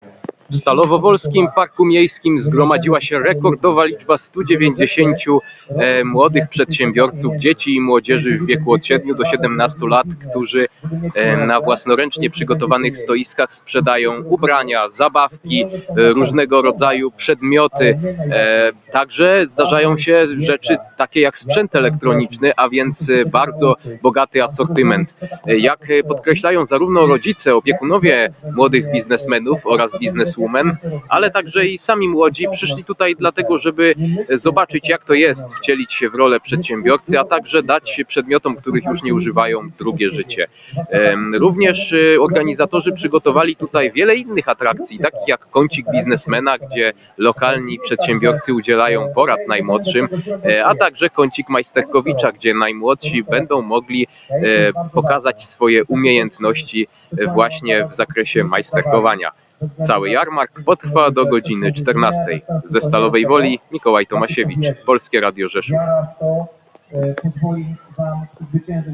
W Parku Miejskim w Stalowej Woli jeszcze dwie godziny potrwa III edycja Jarmarku Młodego Przedsiębiorcy. To okazja dla dzieci i młodzieży, by wcieliły się w rolę sprzedawców, menedżerów i negocjatorów. Na miejscu jest nasz reporter